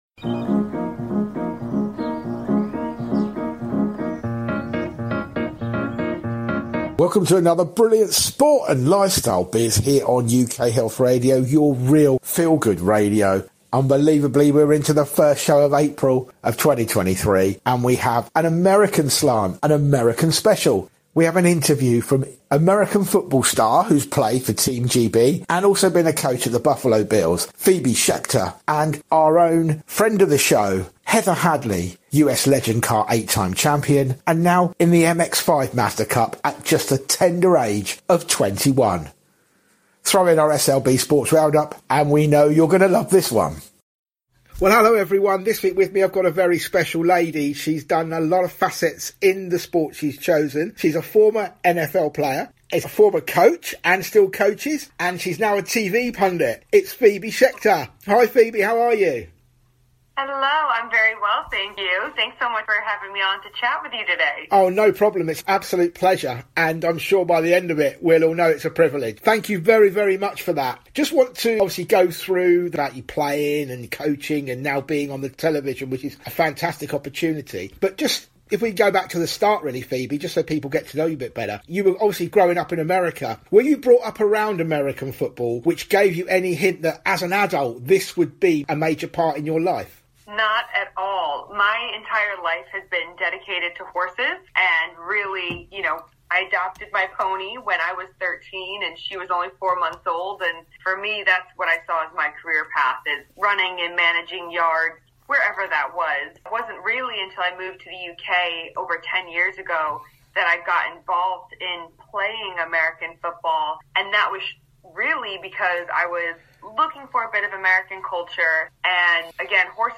Sports and Lifestyle Biz is a fast, informative and action-packed show bringing you all from the world of sport with a hint of lifestyle and biz. We will bring you the best from all the major sporting news and events including Football, Formula 1, Rugby and Cricket to the extreme sports like Chess Boxing. We will bring you features on youth foundations and the development areas for youngsters plus interviews with inspirational women and children.